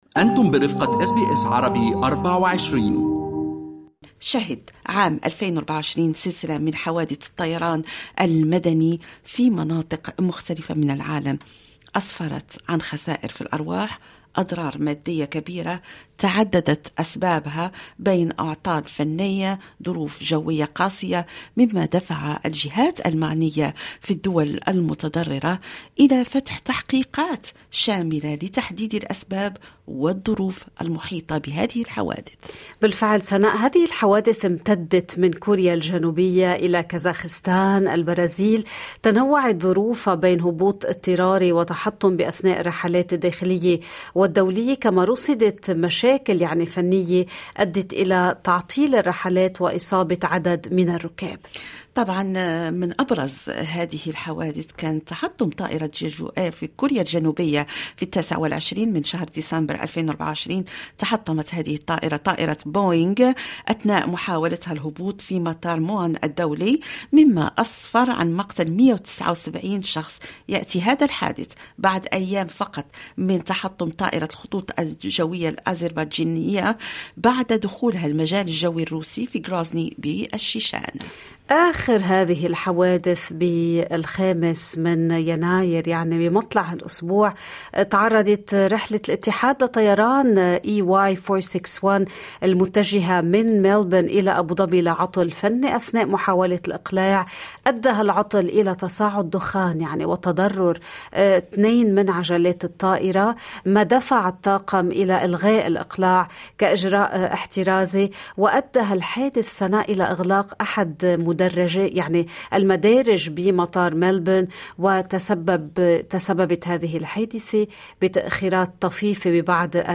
"نسيان براغي أو سرب طيور": مهندس طيران يشرح الأسباب الخفية وراء حوادث الطائرات المتكررة